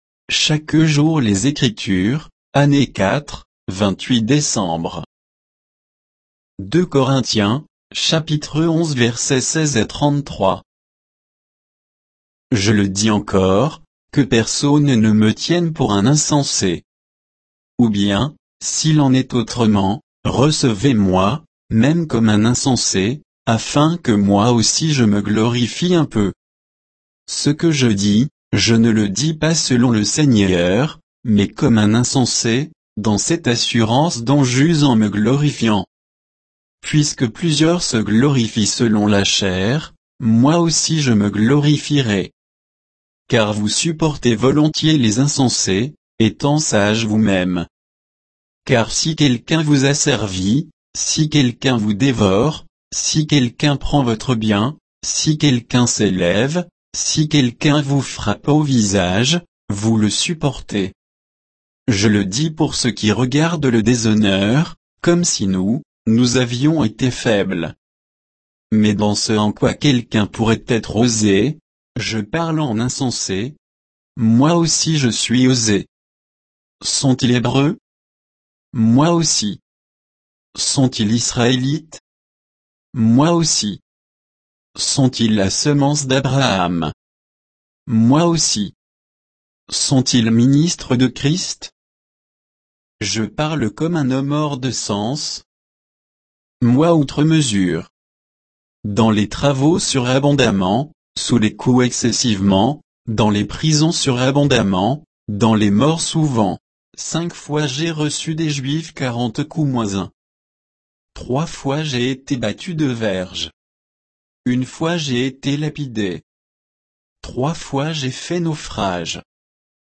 Méditation quoditienne de Chaque jour les Écritures sur 2 Corinthiens 11, 16 à 33